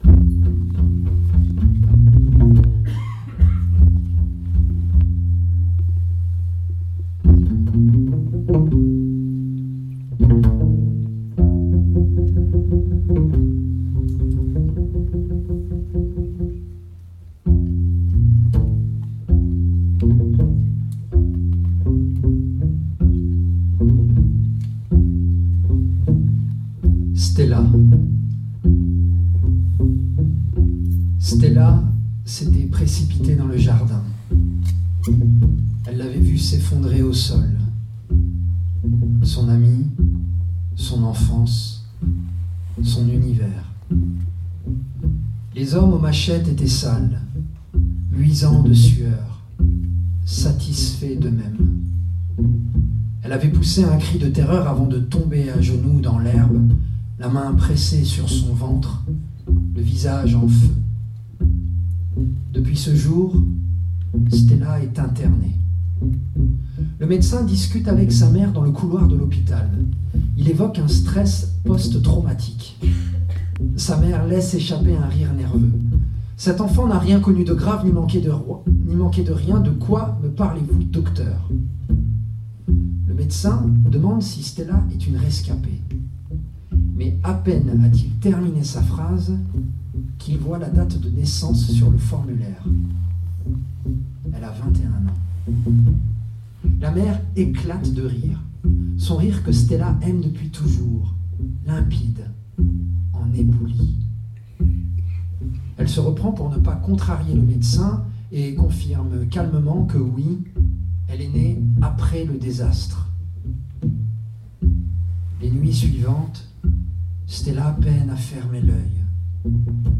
Lecture musicale de Gaël Faye
à la guitare, à la médiathèque François Mitterrand / Latour Maubourg